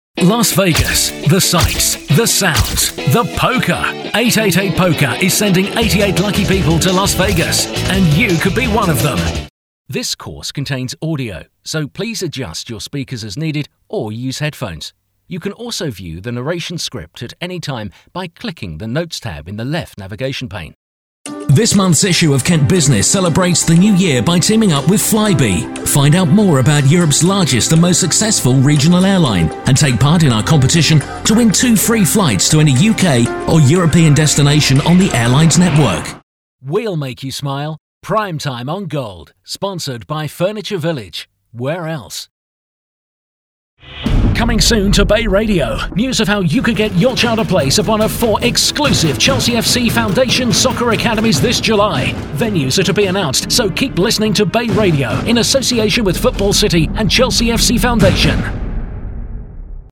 Englisch (UK)
Männlich